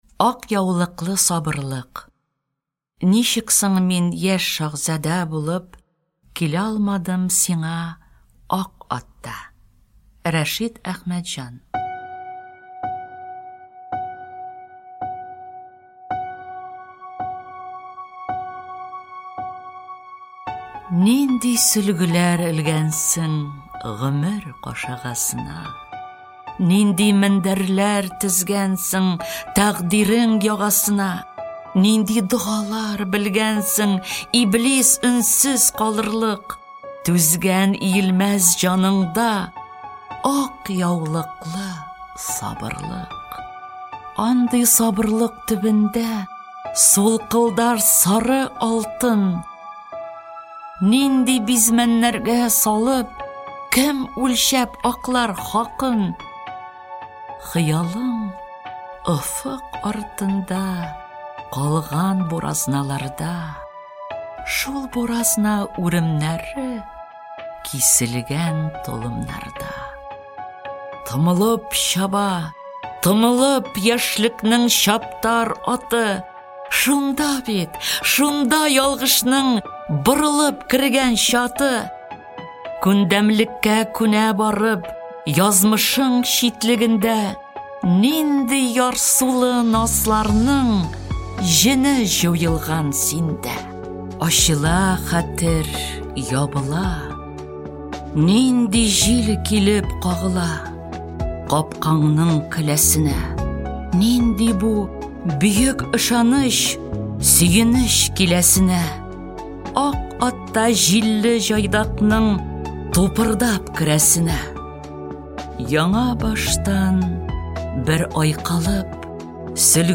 Аудиокнига Шигырьләр | Библиотека аудиокниг